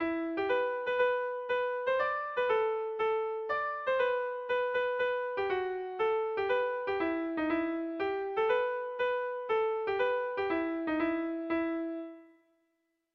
Erlijiozkoa
AB